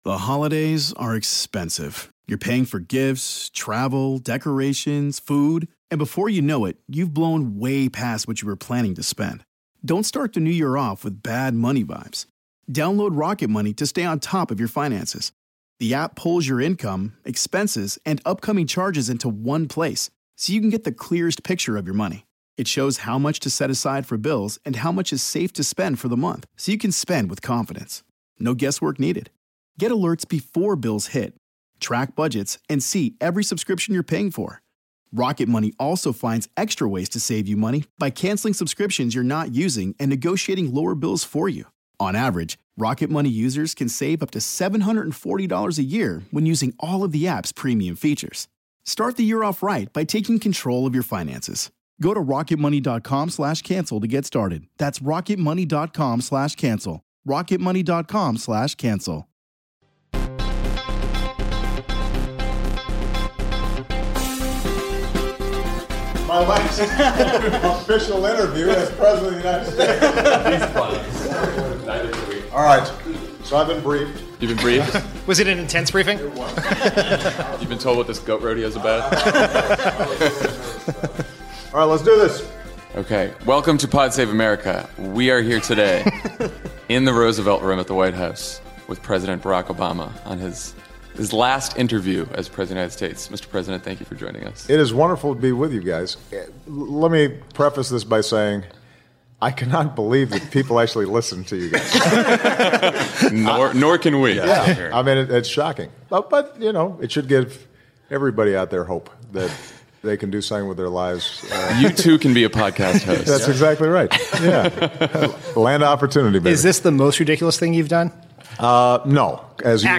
The Pod Save America team sits down with President Obama for his last interview as President.